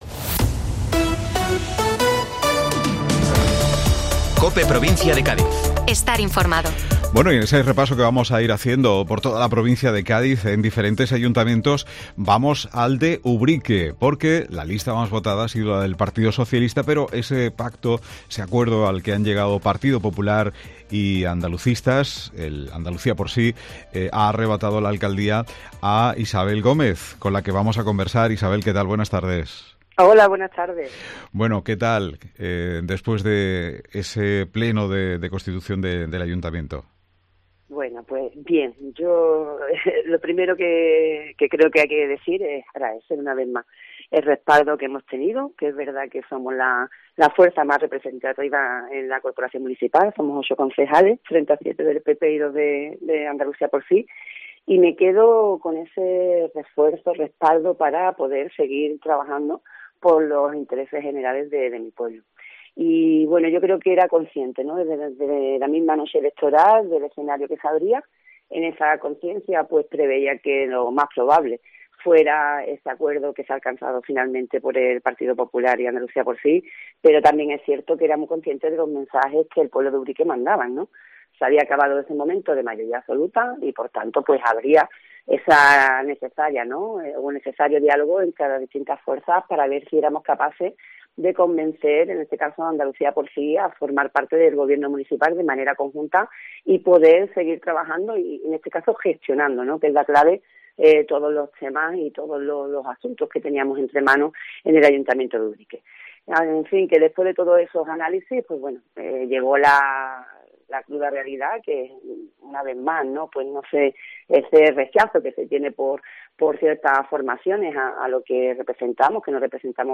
Isabel Gómez, PSOE Ubrique - Pleno constitución gobierno municipal Ubrique